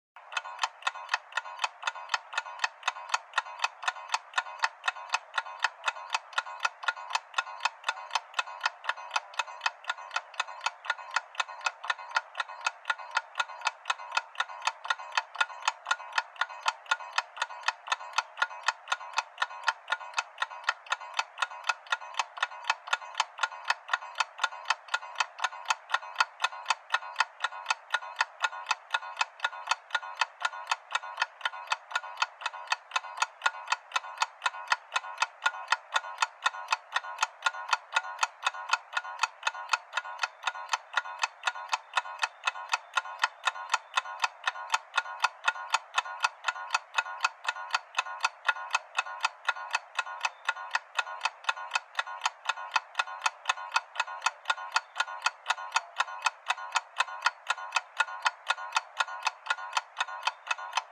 clock metallic ticking sound effect free sound royalty free Memes